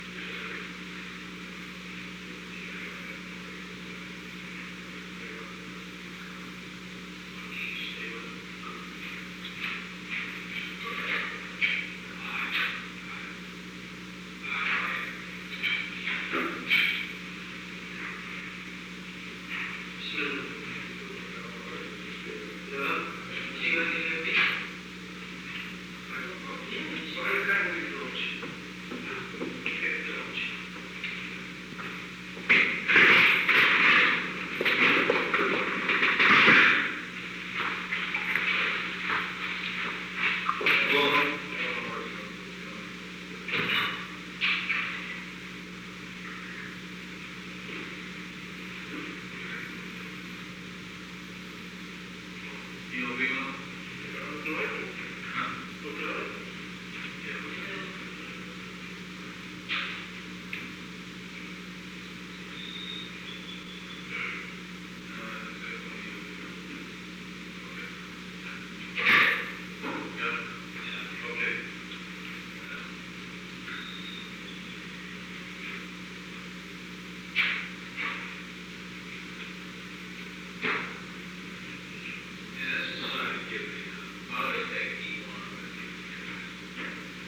Conversation No. 908-26
Location: Oval Office
Unknown United States Secret Service [USSS] agents met.